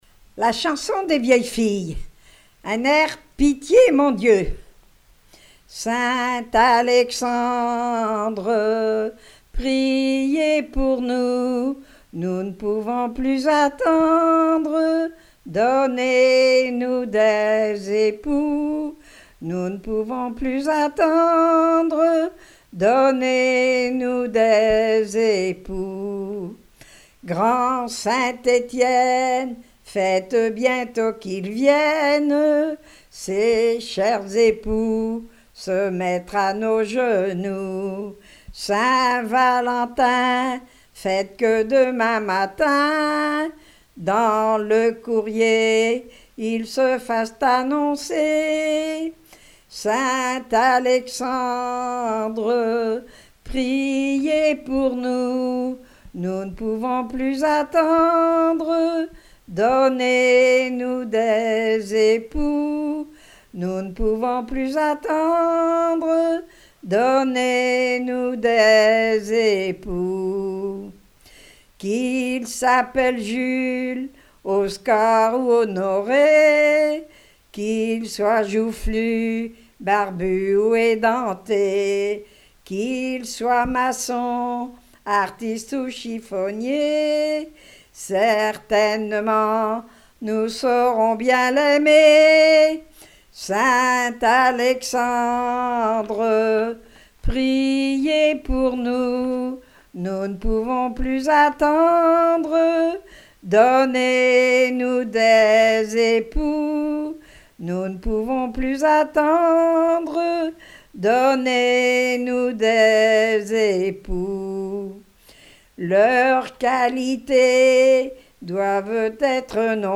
la chanson des vieilles filles
Pièce musicale inédite